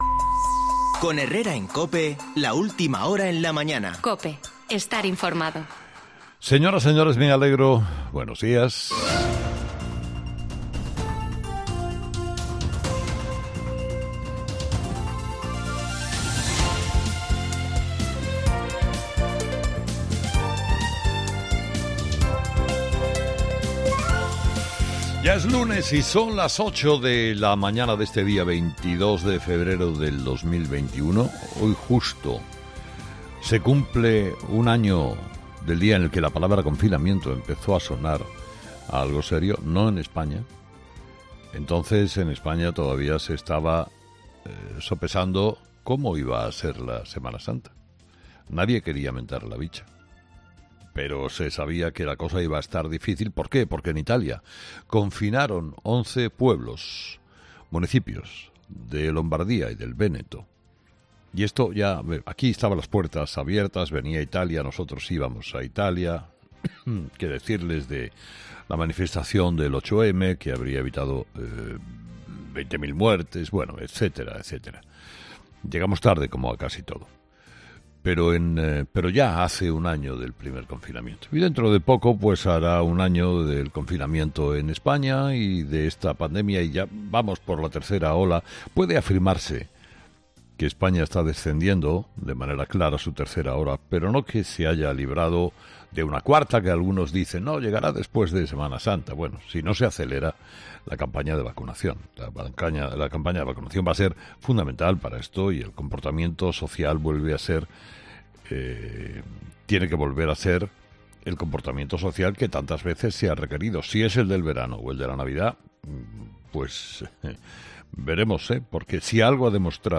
Carlos Herrera relata este lunes en su monólogo el papel del Rey Juan Carlos I en el golpe de Estado del 23-F